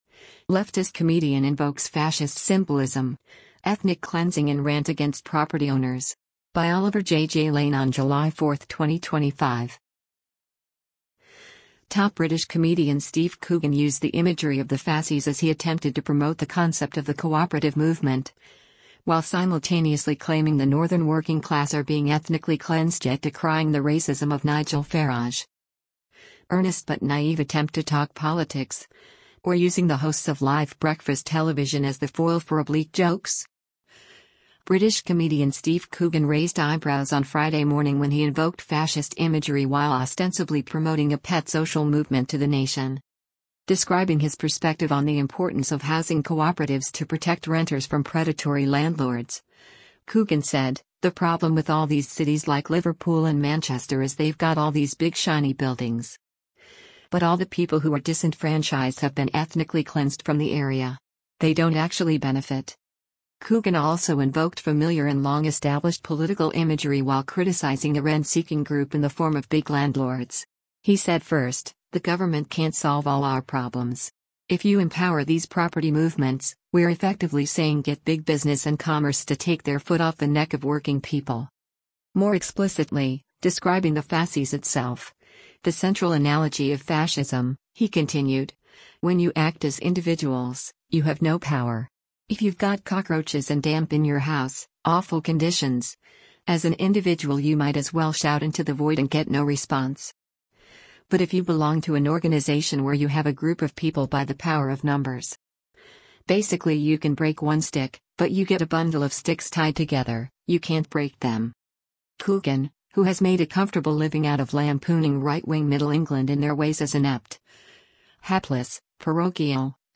Earnest but naïve attempt to talk politics, or using the hosts of live breakfast television as the foil for oblique jokes?